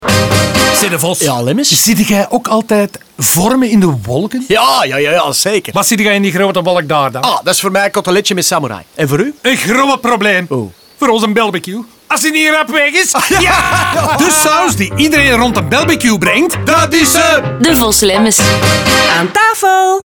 Maar geen Devos & Lemmens campagne zonder een rijk assortiment radiospots natuurlijk. Ook daar vormen de verschillende types een rode draad doorheen de spots.